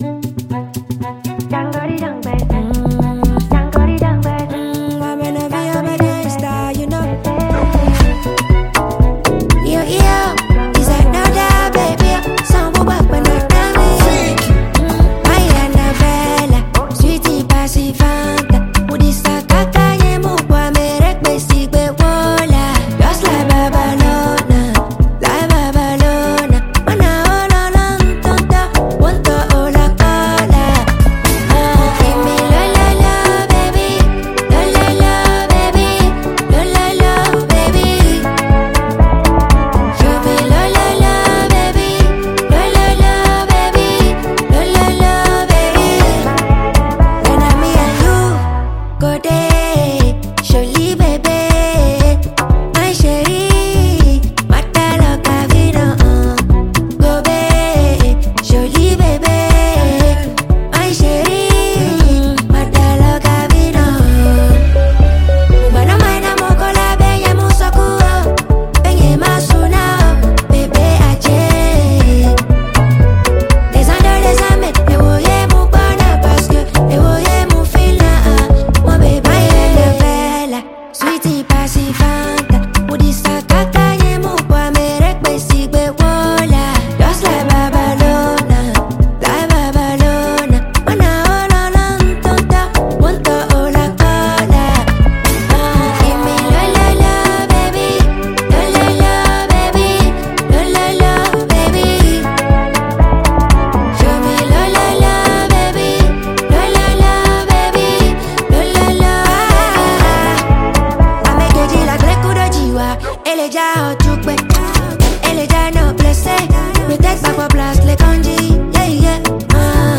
GHANA SONG NEW RELEASES